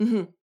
VO_ALL_Interjection_13.ogg